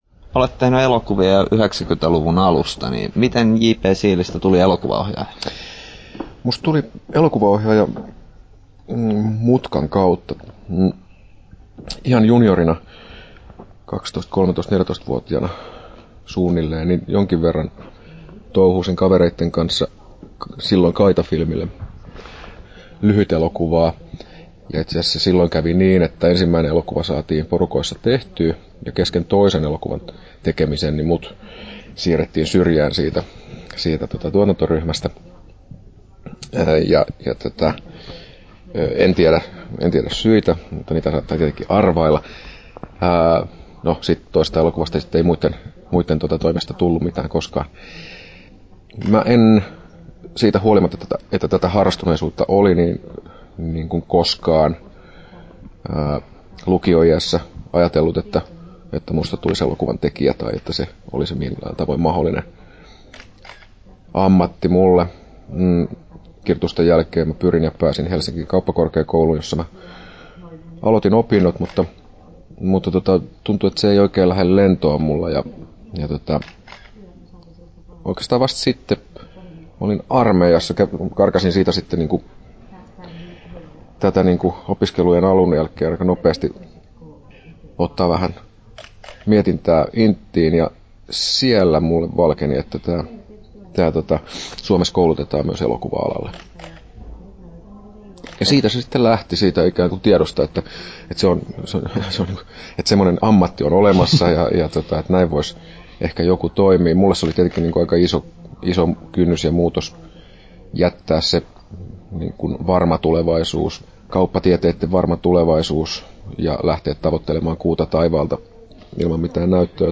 JP Siili ja Härmä • Haastattelut
JP Siilin haastattelu Kesto: 15’10” Tallennettu: 15.2.2012, Turku Toimittaja